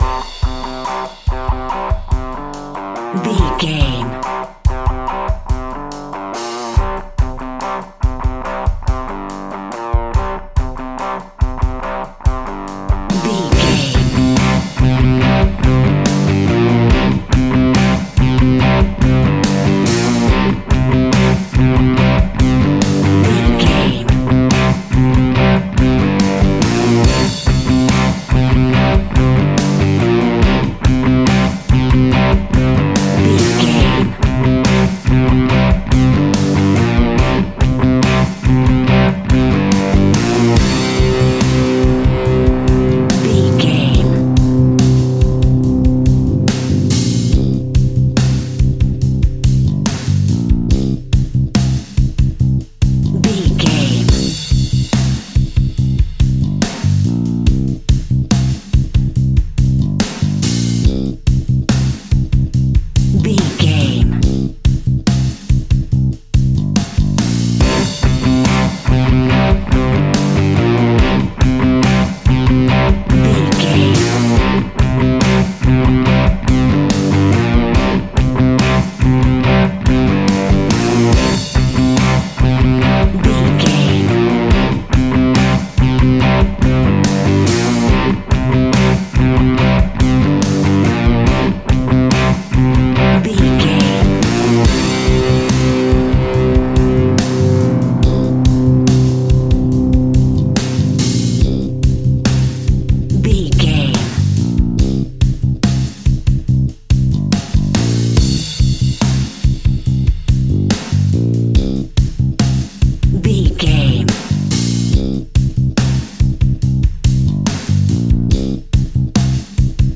Aeolian/Minor
energetic
driving
heavy
aggressive
electric guitar
bass guitar
drums
hard rock
heavy metal
blues rock
distortion
rock instrumentals
distorted guitars
hammond organ